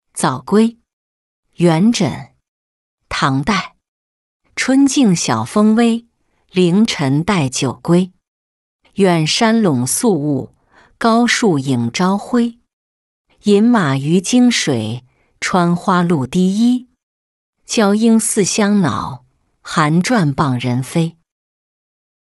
早归-音频朗读